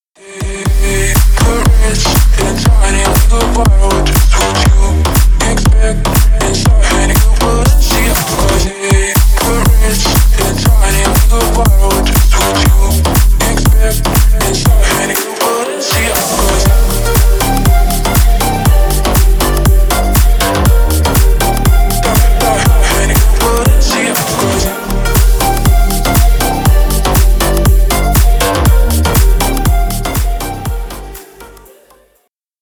бесплатный рингтон в виде самого яркого фрагмента из песни
Ремикс # Поп Музыка
клубные